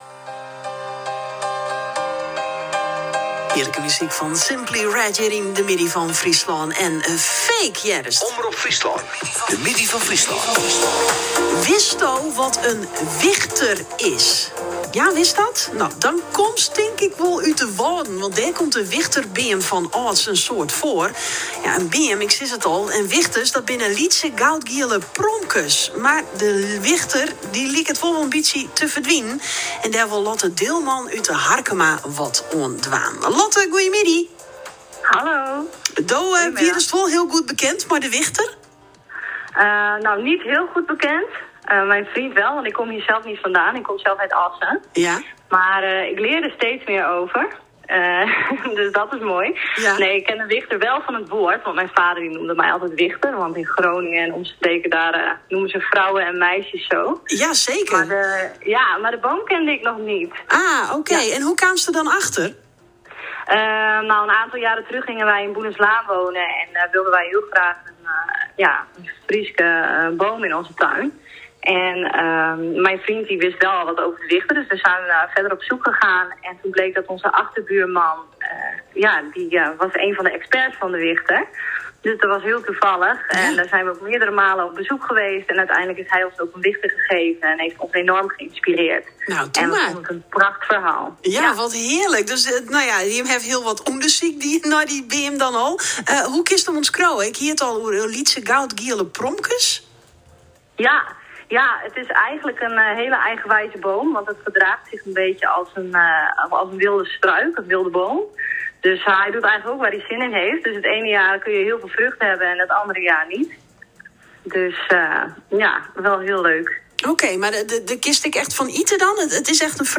Benieuwd naar ons gesprek?